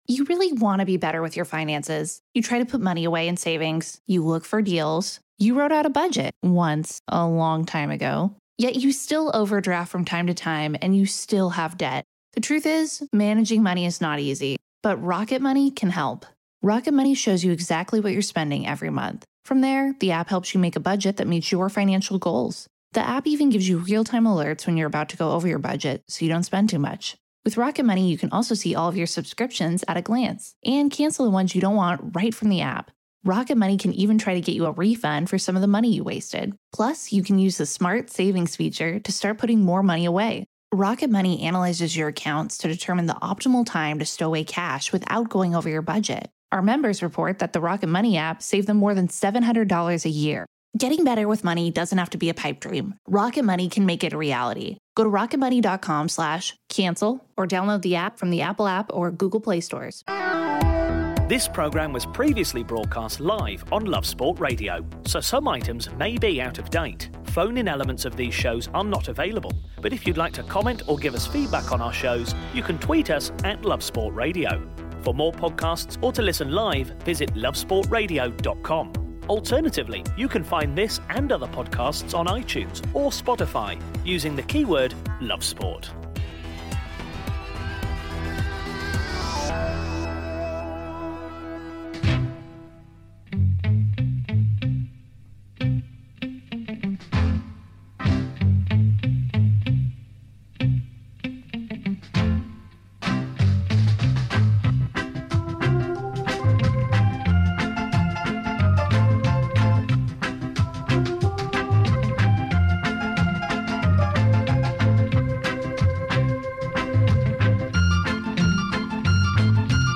in studio. They reviewed the home loss in the League Cup to Manchester United and chatted about fixtures going forward.